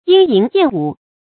鶯吟燕舞 注音： ㄧㄥ ㄧㄣˊ ㄧㄢˋ ㄨˇ 讀音讀法： 意思解釋： 見「鶯歌燕舞」。